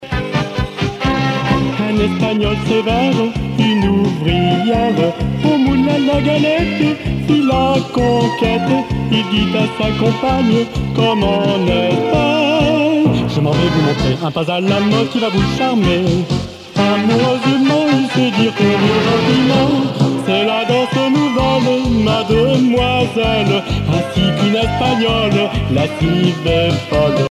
danse : matchiche (ou maxixe)
Pièce musicale éditée